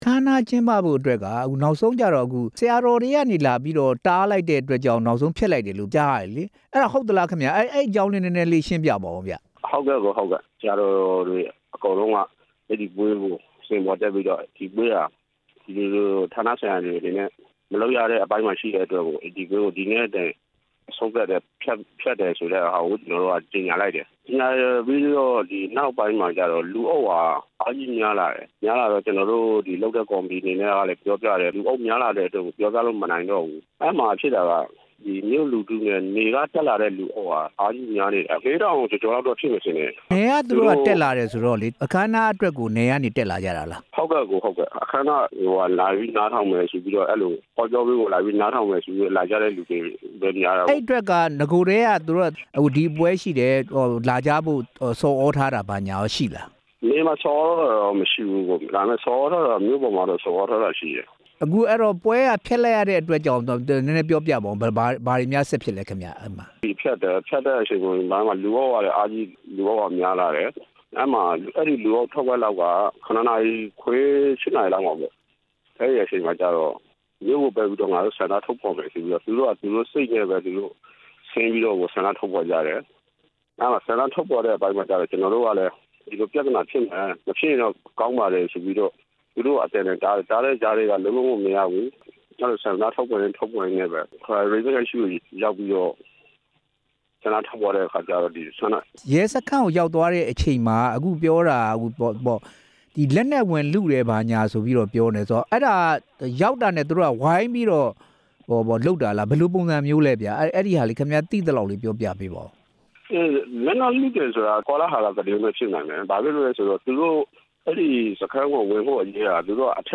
ပဋိပက္ခဖြစ်ပွားခဲ့တဲ့ အခြေအနေကို ကိုယ်တိုင်ကြုံခဲ့ရတဲ့ မြောက်ဦးမြို့ခံ အခမ်းအနား ဖြစ်မြောက်ရေး ကော်မတီဝင်တစ်ဦးကို